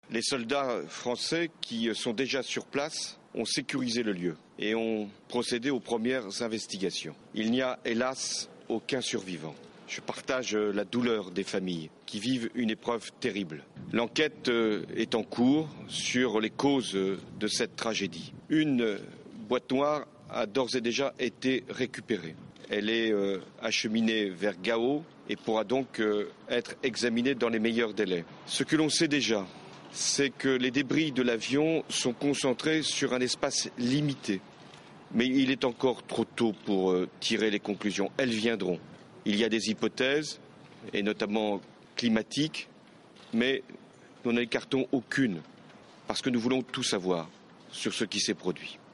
Déclaration de François Hollande